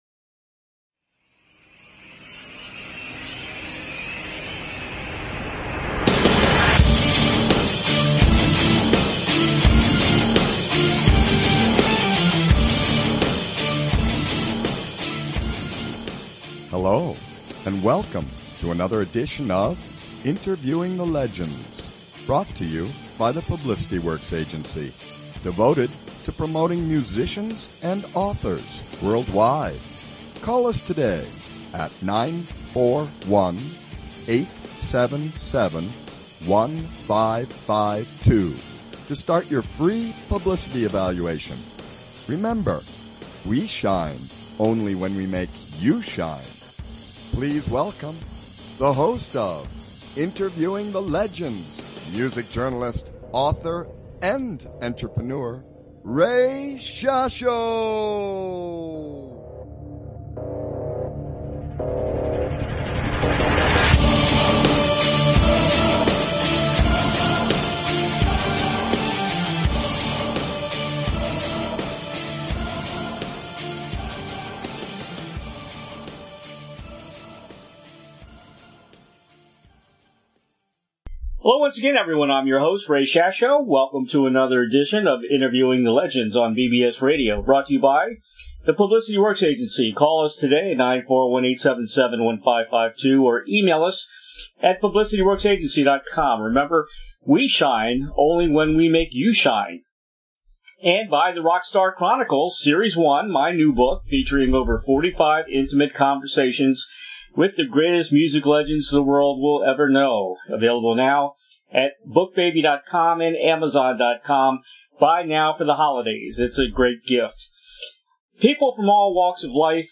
Interviewing The Legends , January 19, 2021